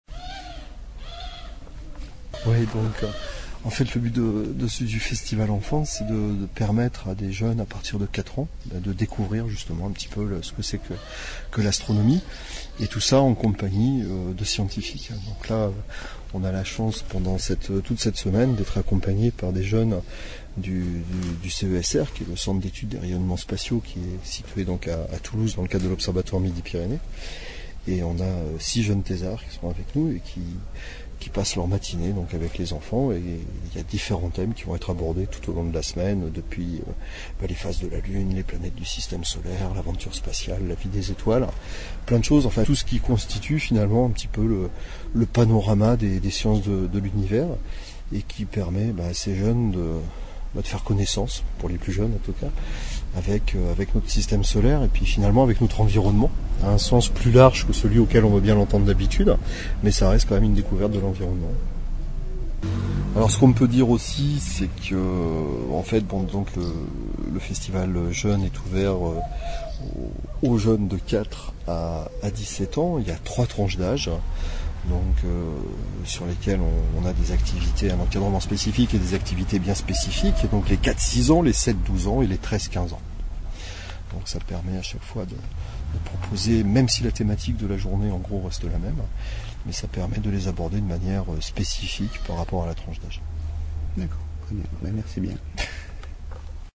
XVIIème festival d’astronomie de Fleurance